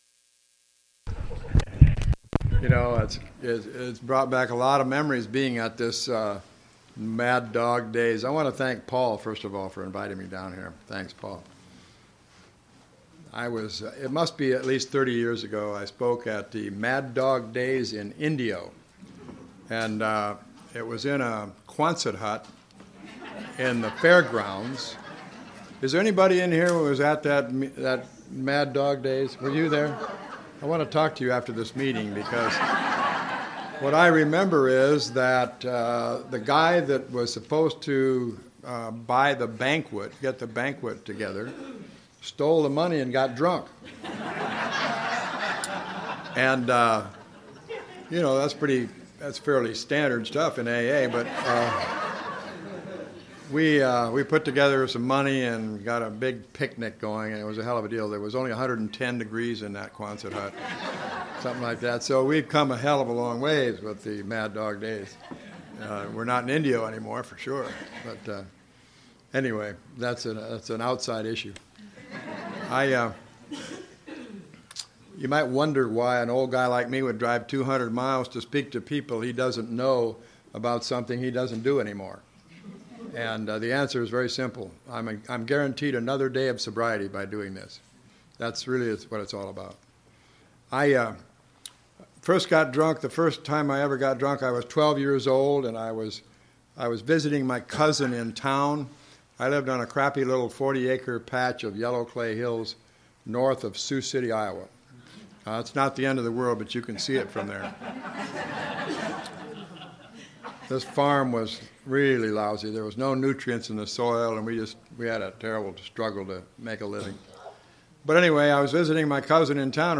AA - Long Timers Meeting